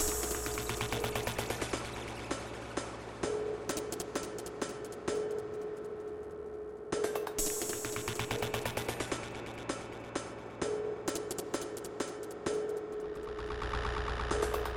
它 Moombahton Combi
Tag: 110 bpm Dubstep Loops Groove Loops 775.38 KB wav Key : Unknown